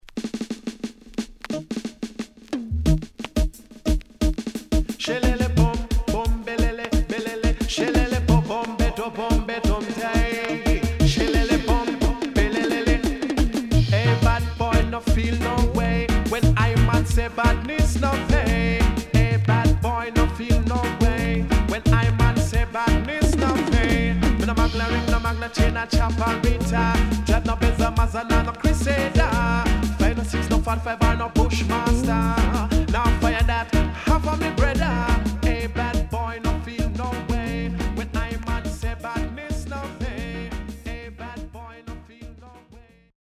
HOME > Back Order [DANCEHALL LP]
SIDE B:所々チリノイズがあり、少しプチパチノイズ入ります。